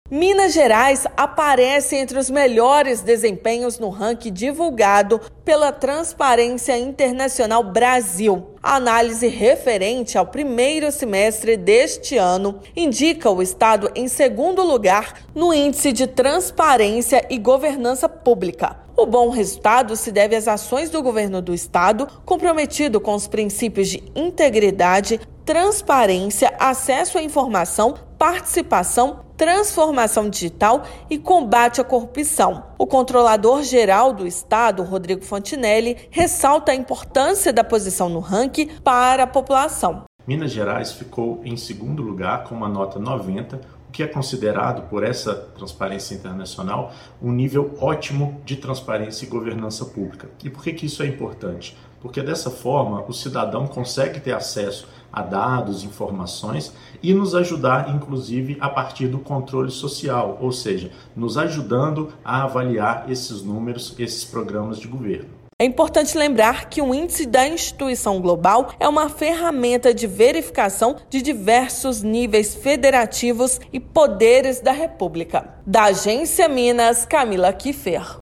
Governo de Minas tem desempenho considerado ótimo na classificação geral e ficou na segunda posição do índice divulgado por instituição internacional. Ouça matéria de rádio.